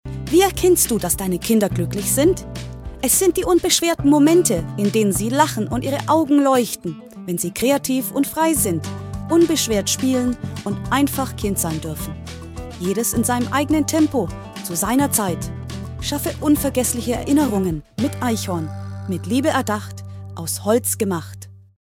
Qualität: Unsere KI-Stimmen liefern Ergebnisse, die kaum von menschlichen Sprechern zu unterscheiden sind.
KI Frau Deutsch:
KI-Frau-DE.mp3